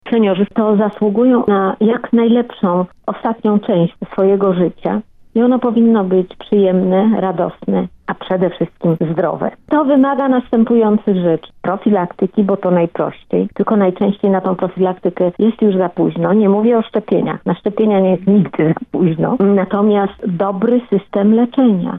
Słyszymy o tym w wywiadzie przeprowadzonym przez Stowarzyszenie Dziennikarzy dla Zdrowia z prof. dr hab. n. med. Alicją Chybicką, posłanką na Sejm oraz wiceprzewodniczącą podkomisji stałej do spraw zdrowia osób starszych.